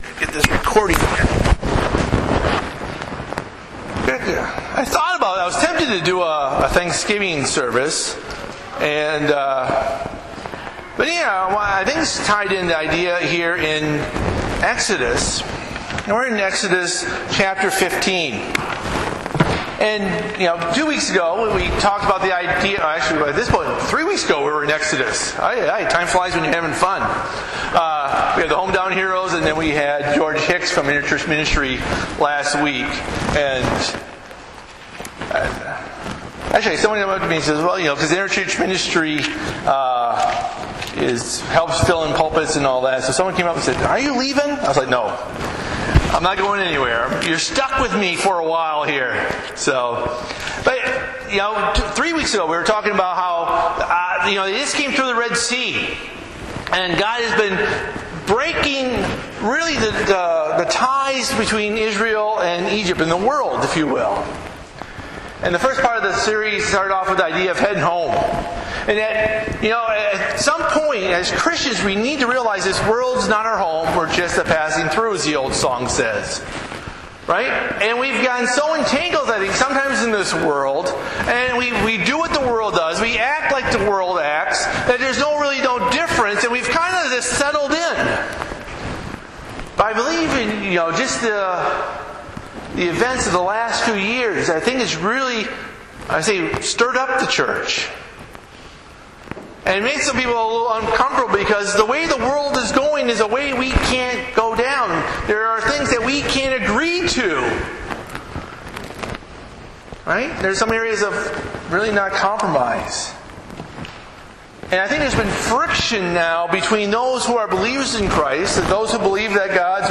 Bible Text: Exodus 15:22-27 | Preacher